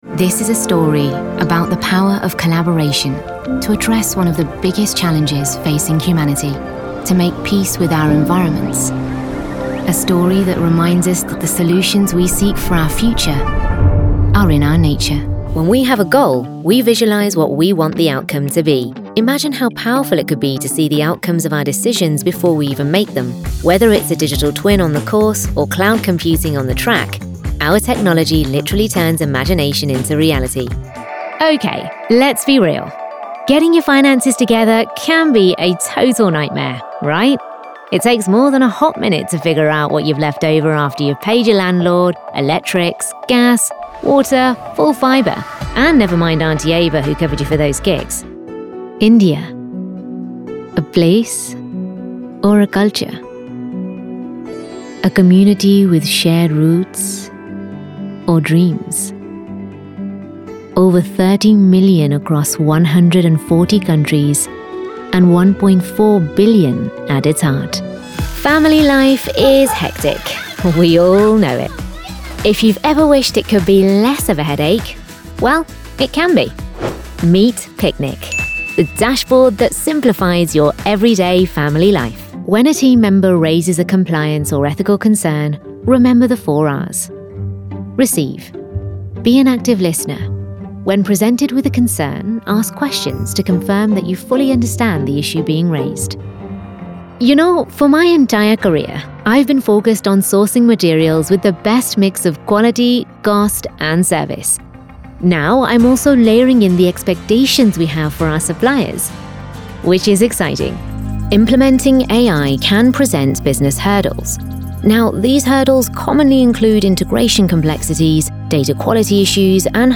Inglés (Británico)
Natural, Travieso, Urbana, Amable, Cálida
Corporativo